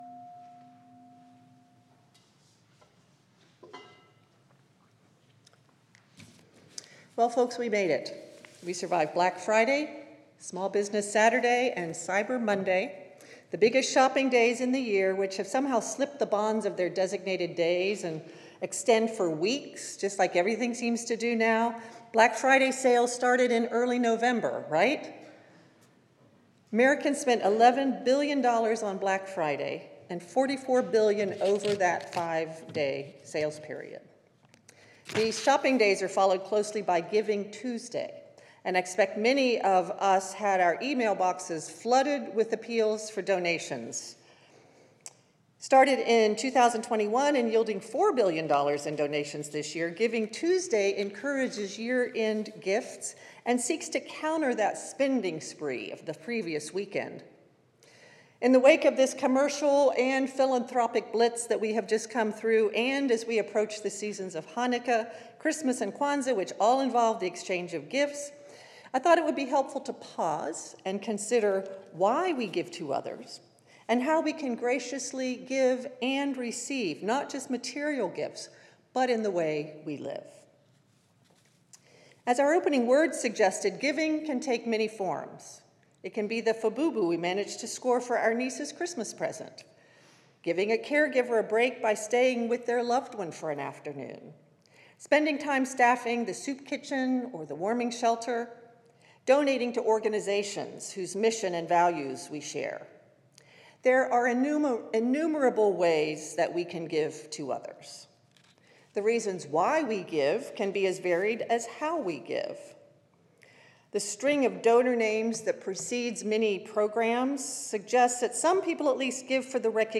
December ushers in a season full of holidays when many of us will both give and receive gifts. This service looks at the challenge of giving in a culture that encourages taking, the joy of discovering how much you have to give, and the grace in receiving gifts from others.